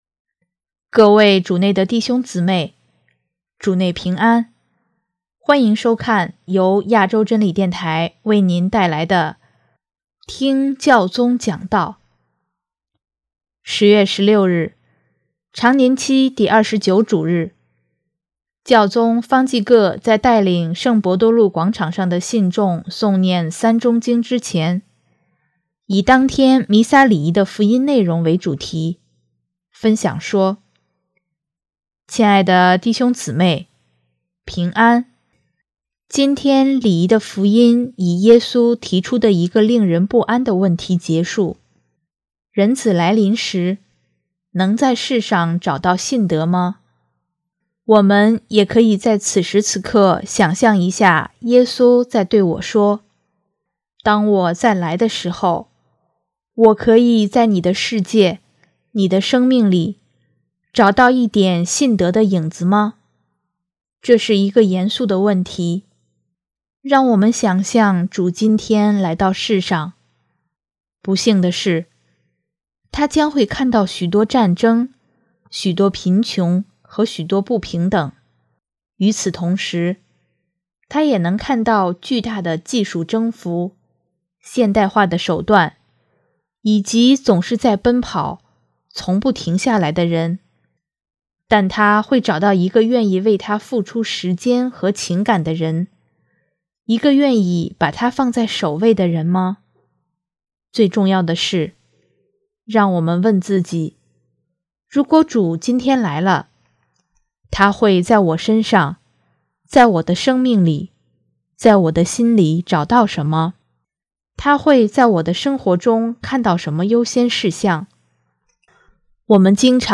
10月16日，常年期第二十九主日，教宗方济各在带领圣伯多禄广场上的信众诵念《三钟经》之前，以当天弥撒礼仪的福音内容为主题，分享说：